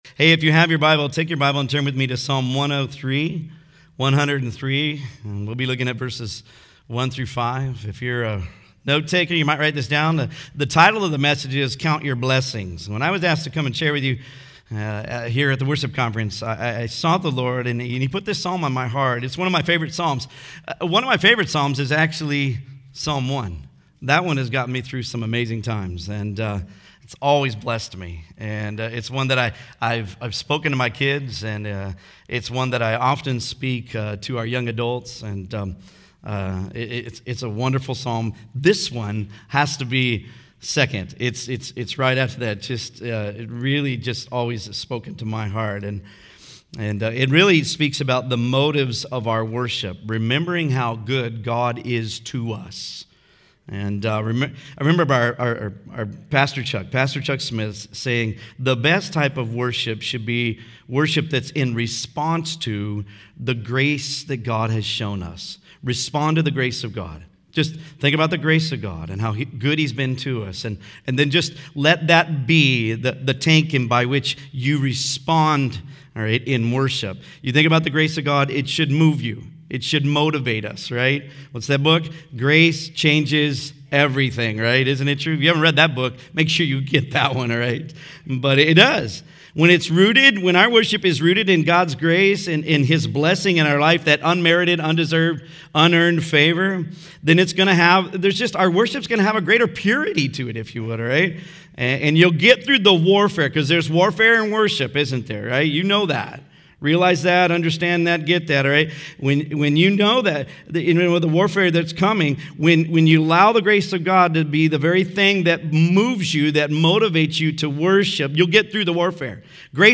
Home » Sermons » Psalm 103: Count Your Blessings
Conference: Worship Conference